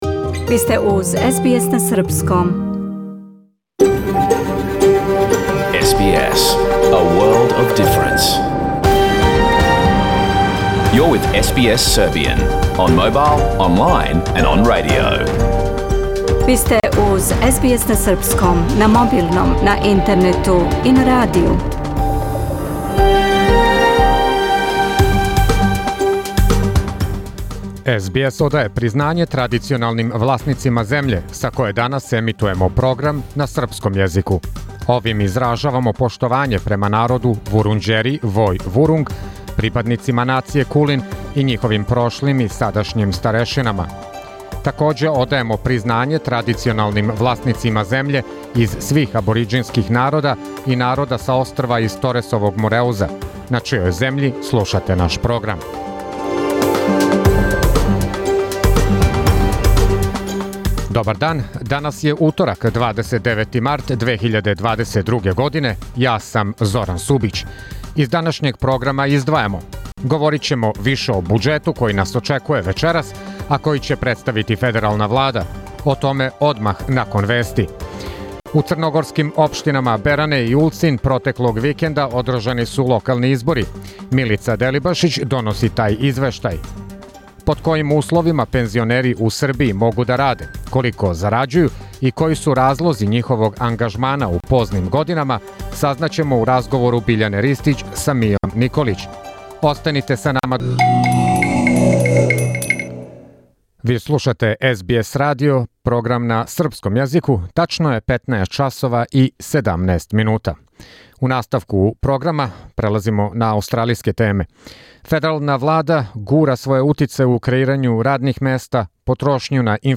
Програм емитован уживо 29. марта 2022. године
Ако сте пропустили нашу емисију, сада можете да је слушате у целини као подкаст, без реклама.